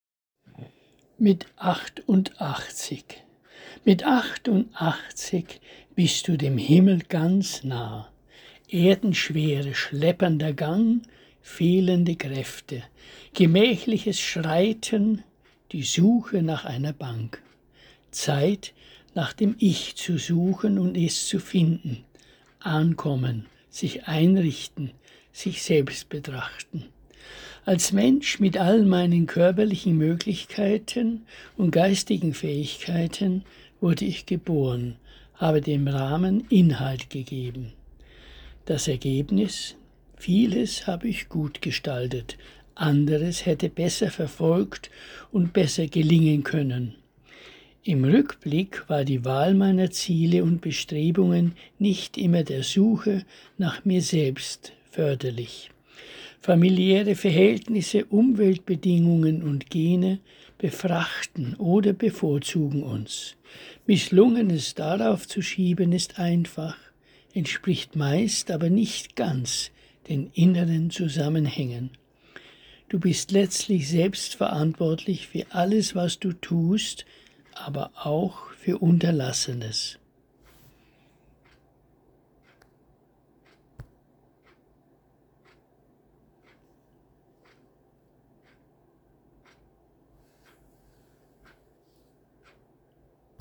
Lesung eigener Gedichte